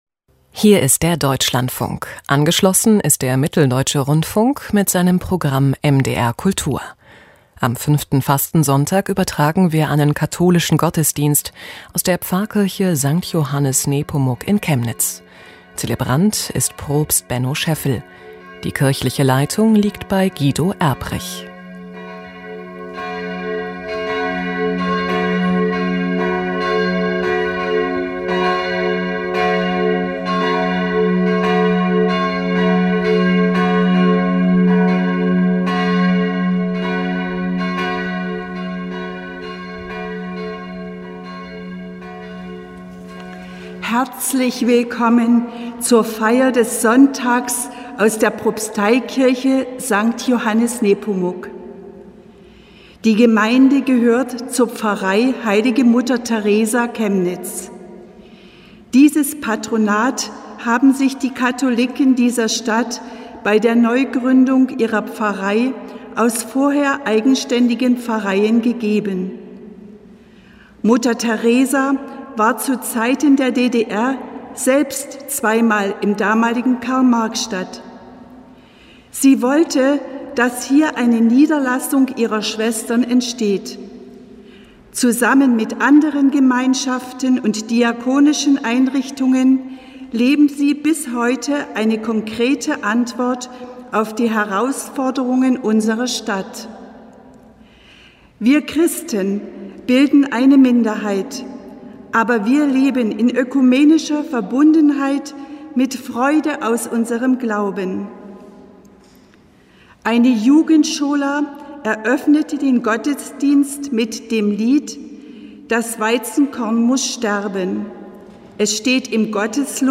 Kath. Gottesdienst vom 21.03.2021